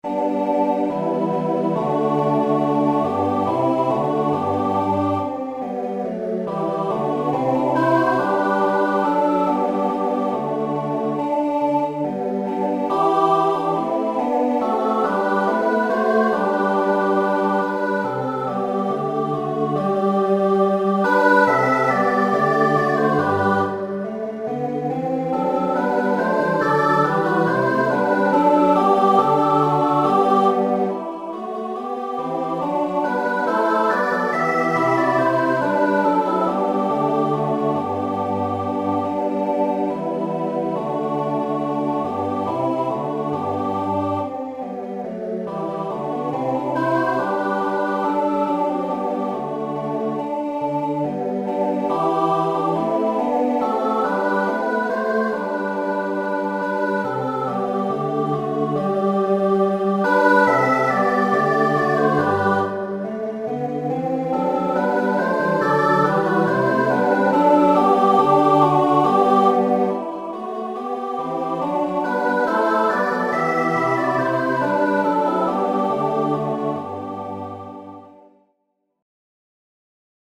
SATB a cappella, with descant